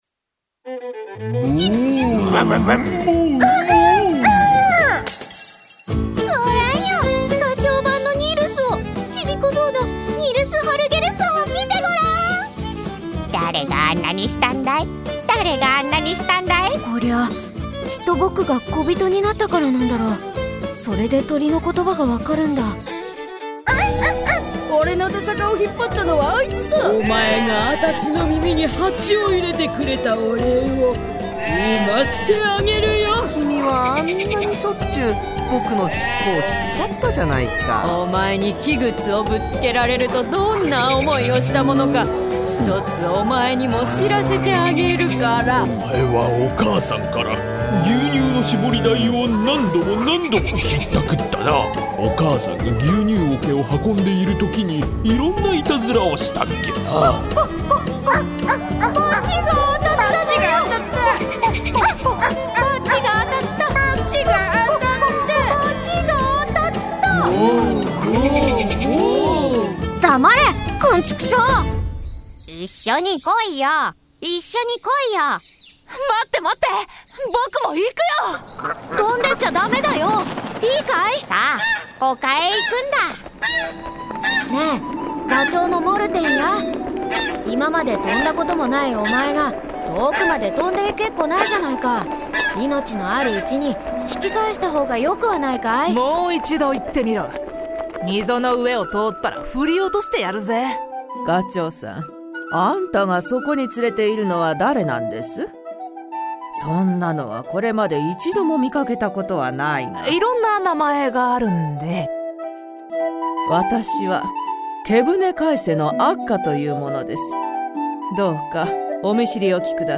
[オーディオブック] ニルスのふしぎな旅
★bgmや効果音が入った、楽しくおもしろい!聴く絵本です。